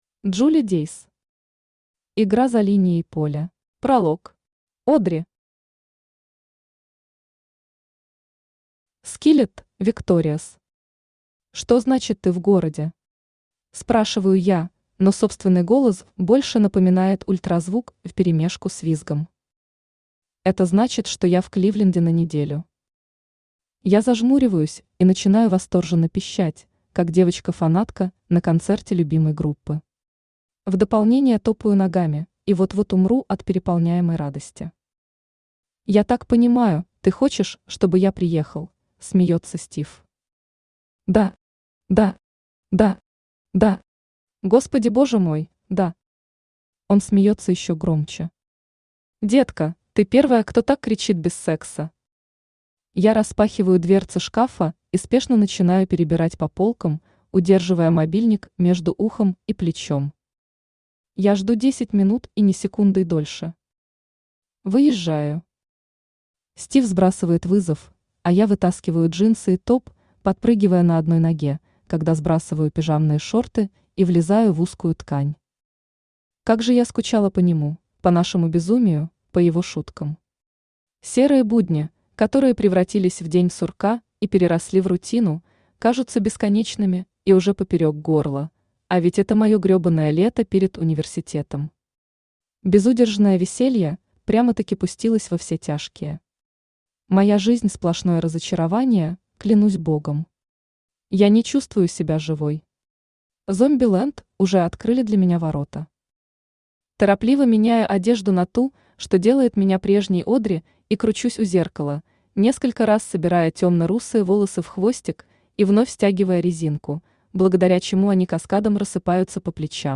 Аудиокнига Игра за линией поля | Библиотека аудиокниг
Aудиокнига Игра за линией поля Автор Джули Дейс Читает аудиокнигу Авточтец ЛитРес.